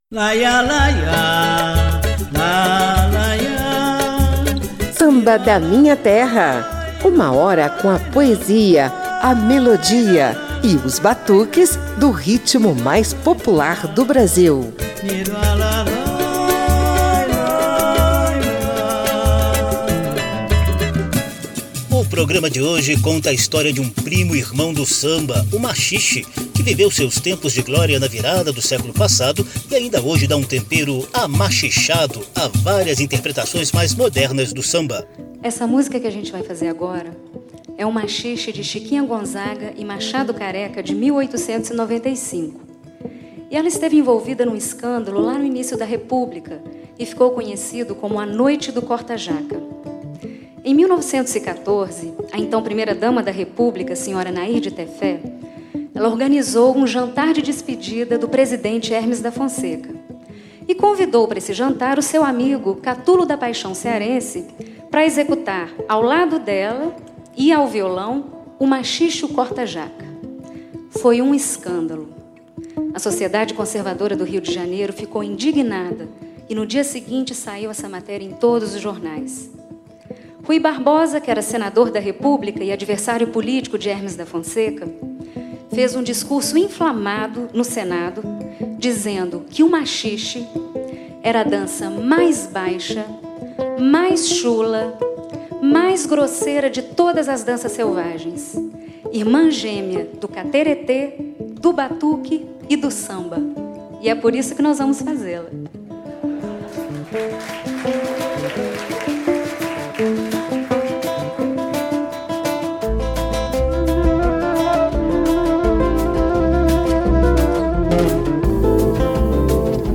Primo-irmão do samba, o maxixe – também conhecido como “tango brasileiro” e “corta-jaca” – é um misto de ritmo e dança surgido no fim do século XIX.